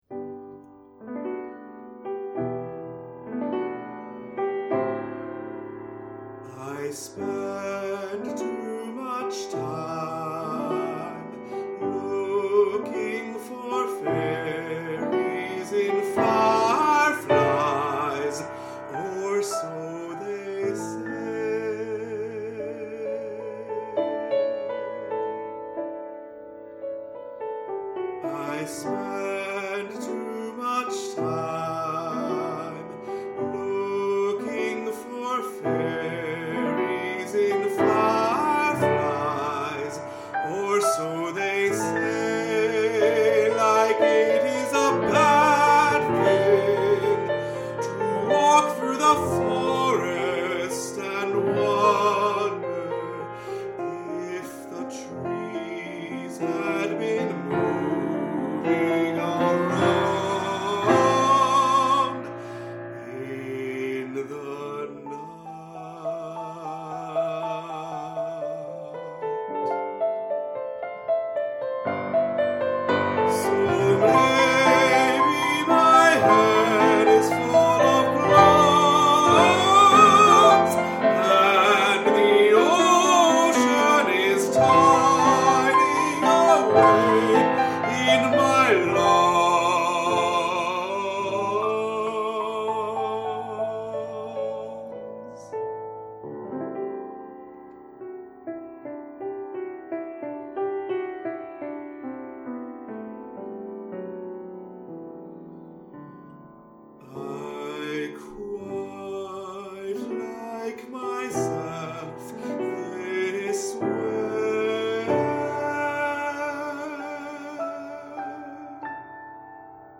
Tenor & piano